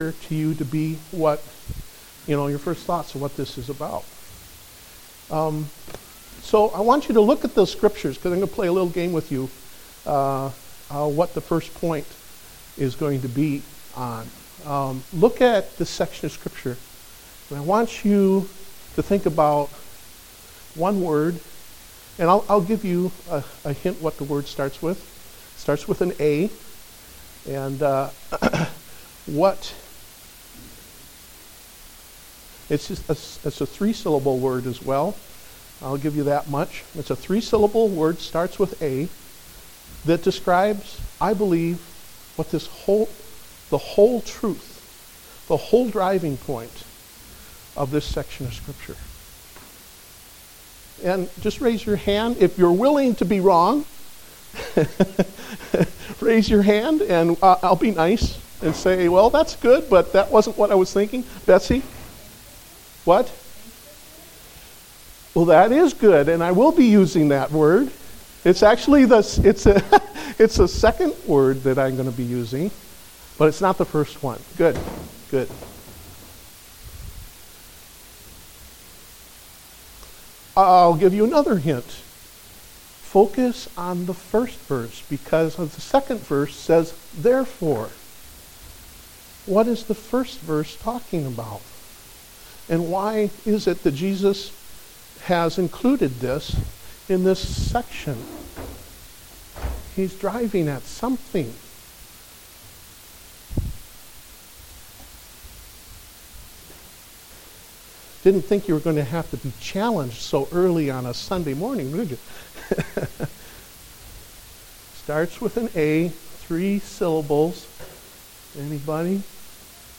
Date: August 23, 2015 (Adult Sunday School)